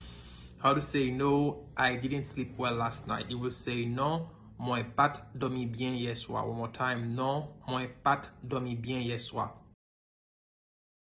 Pronunciation:
Listen to and watch “Non, mwen pa t dòmi byen yè swa” pronunciation in Haitian Creole by a native Haitian  in the video below: